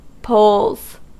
Ääntäminen
Ääntäminen US : IPA : /ˈpoʊlz/ UK : IPA : /ˈpəʊlz/ Haettu sana löytyi näillä lähdekielillä: englanti Käännöksiä ei löytynyt valitulle kohdekielelle.